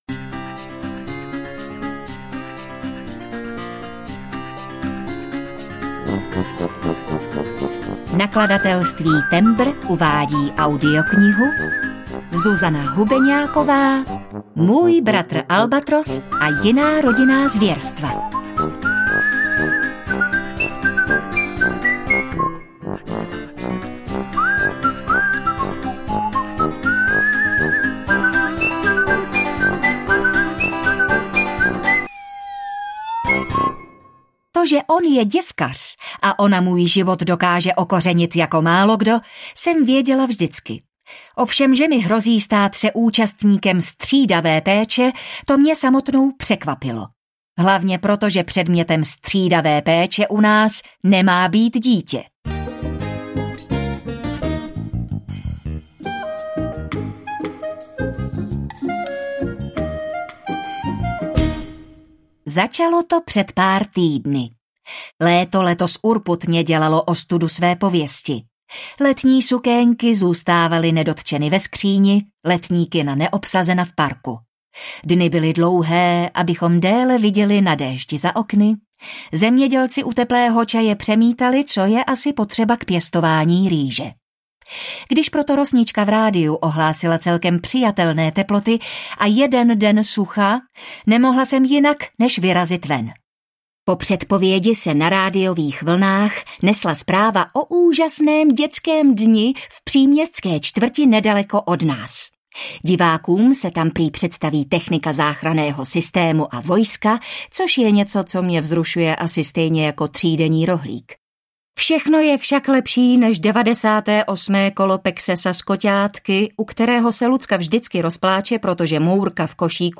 Ukázka z knihy
muj-bratr-albatros-a-jina-rodinna-zverstva-audiokniha